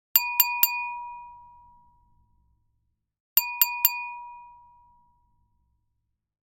Wine Glass Three Taps
Bottle Ding Dinging Glass Impact Ring Ringing Tapping sound effect free sound royalty free Sound Effects